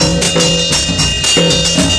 DrumSet1-2sec.mp3